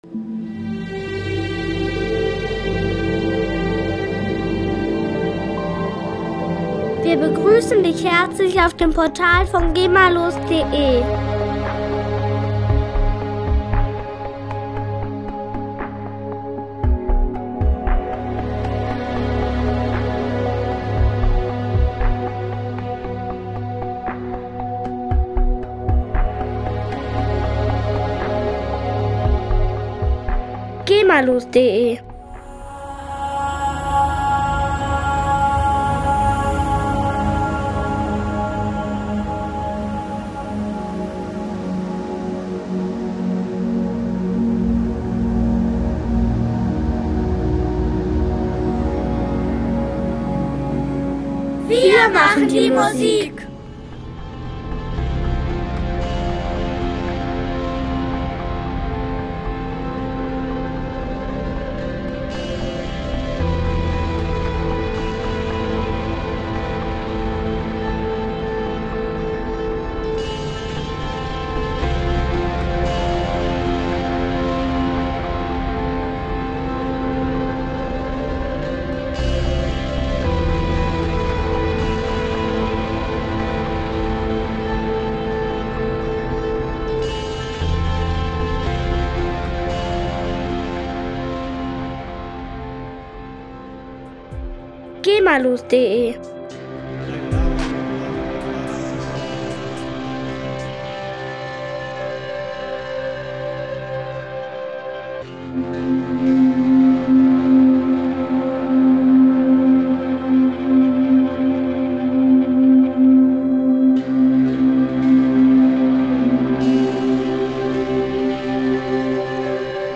gemafreie CD mit Soundtracks und Filmmusik
Musikstil: Ambient / Filmmusik